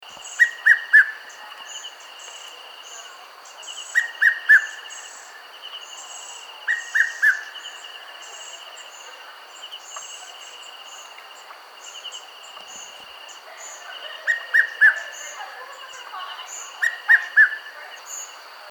Rain bird
A few days back we have started hearing the by now familiar ‘wip-wip-weeu’ that the rain bird or red-chested cuckoo (Cuculus solitarius) make endlessly at the time the rains should start in Zimbabwe.
red-chested-cuckoo-cuculus-solitarius.mp3